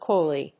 音節cóy・ly 発音記号・読み方
/ˈkɔɪli(米国英語), ˈkɔɪli:(英国英語)/